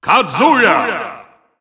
Announcer pronouncing Kazuya's name in Russian.
Kazuya_Russian_Announcer_SSBU.wav